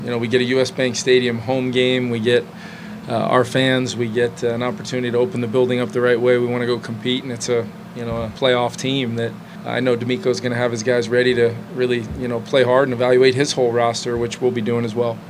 Vikings head coach Kevin O’Connell says this week’s preseason game is something they want to perform well in.